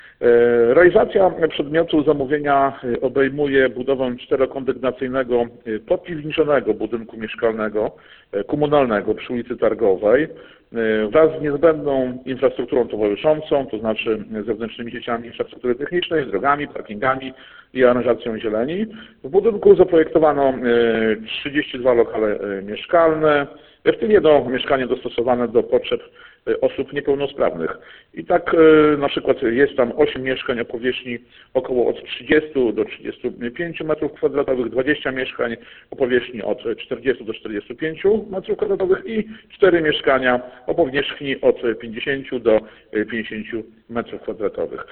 Jakie mieszkania znaleźć się mają w nowym bloku komunalnym – przypomina burmistrz Latarowski.